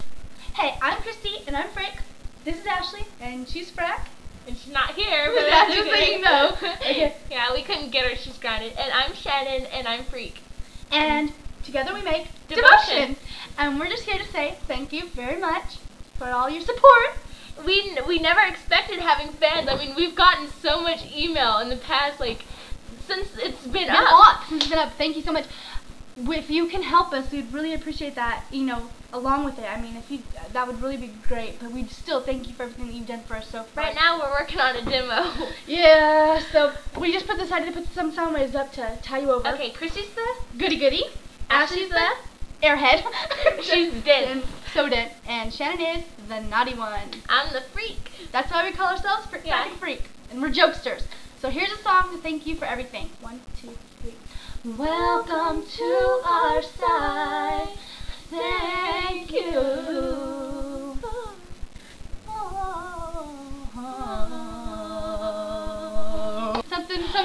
This is a sound of us welcoming you to our site.